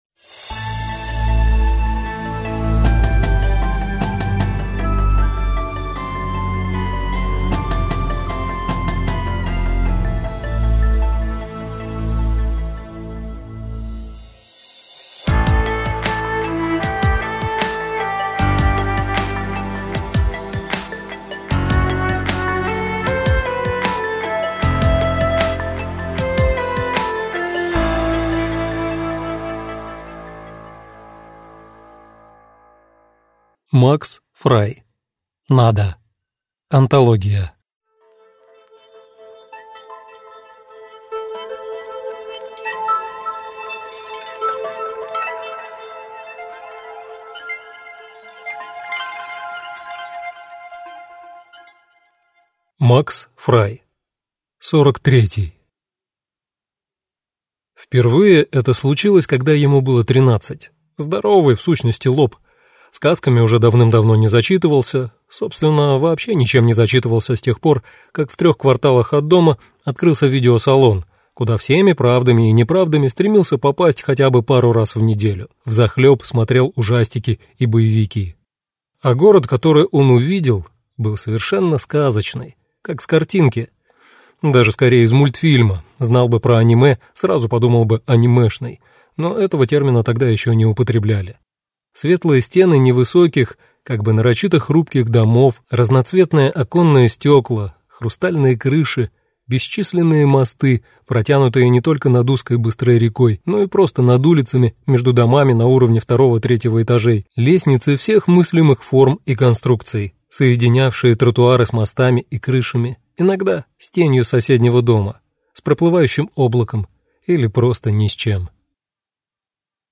Аудиокнига Nada (сборник) | Библиотека аудиокниг